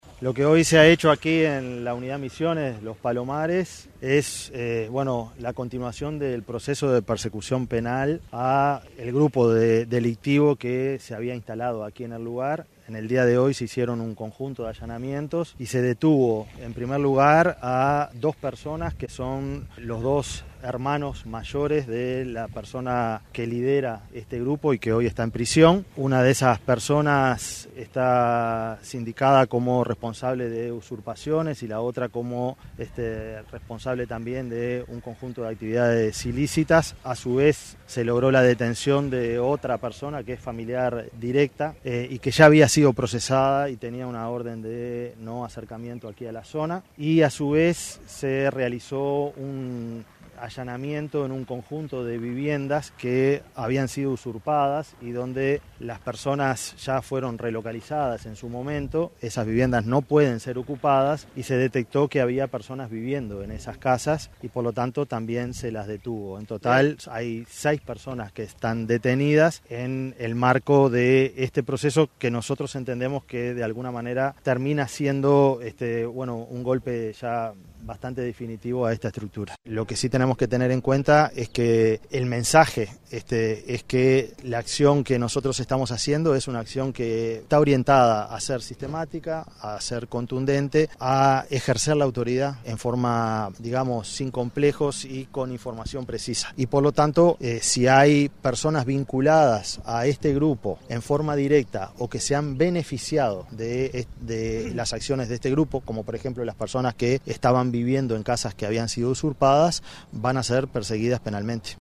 El directo de convivencia ciudadana Gustavo Leal dijo que el operativo se hizo en base a información de vecinos y en base a información táctica recibida